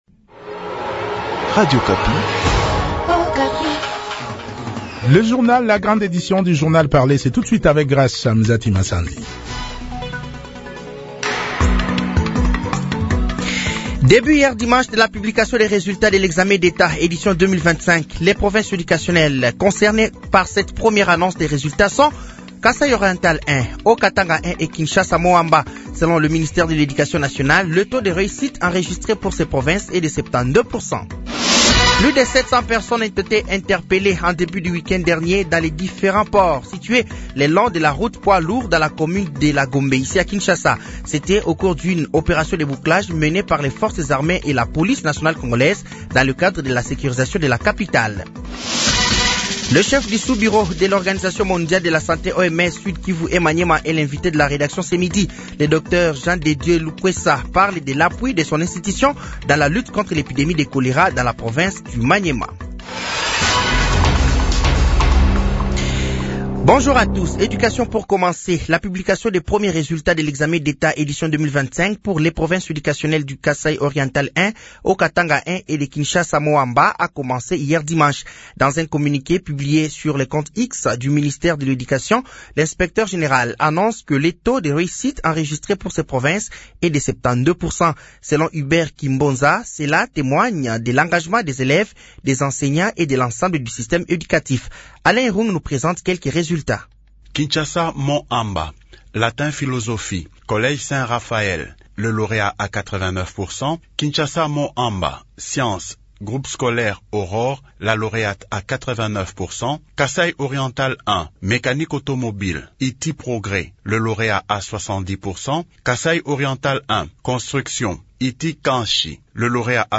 Journal français de 12h de ce lundi 04 août 2025